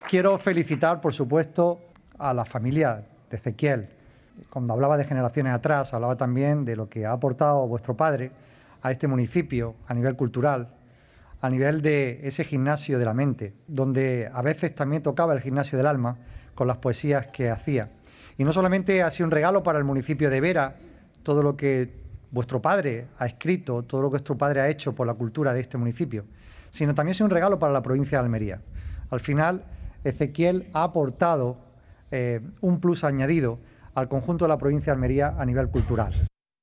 El municipio de Vera hoy ha vivido un día histórico con la inauguración de la nueva Biblioteca Municipal Ezequiel Navarrete Garres que ha abierto sus puertas gracias a la colaboración institucional entre la Diputación y el ayuntamiento veratense.
22-04_biblioteca_vera_presidente_2.mp3